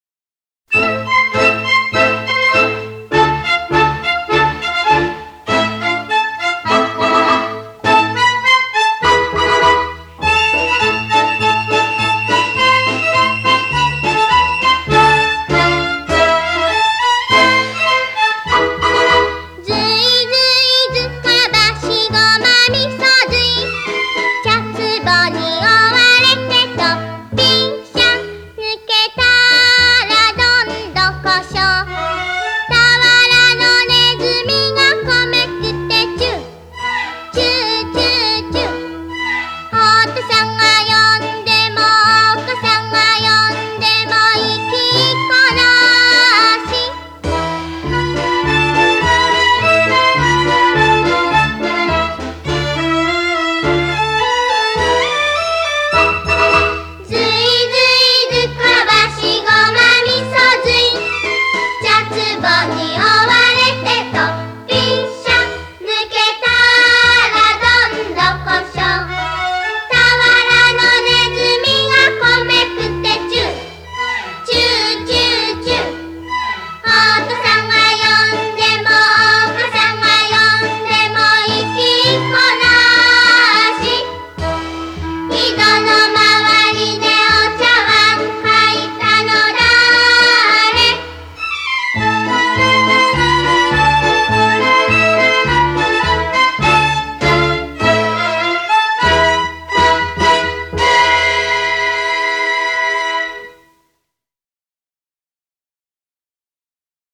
Une autre chanson pour enfant que j’aime beaucoup.